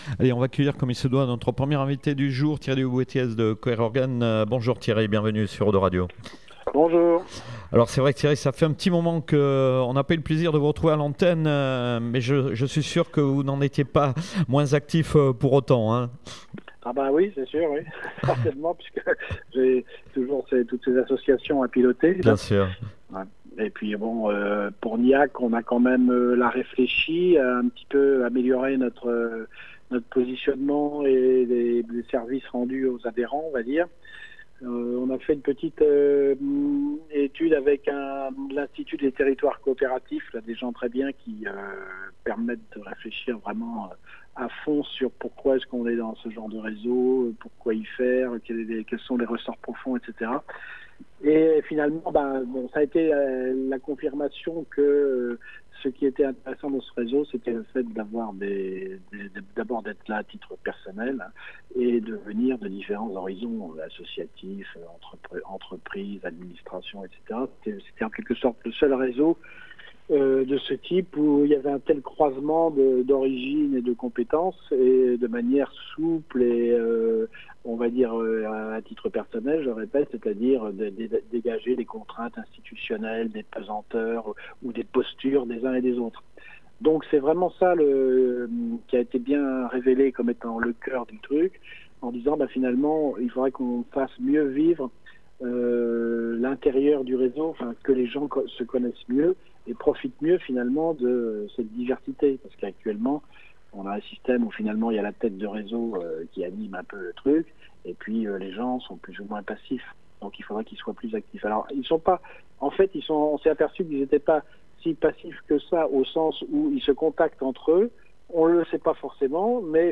Nouvelle interview GNIAC / 02 Radio avec les actus GNIAC (nouveau positionnement et services aux membres)